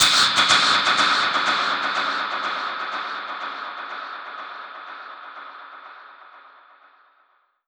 Index of /musicradar/dub-percussion-samples/125bpm
DPFX_PercHit_C_125-06.wav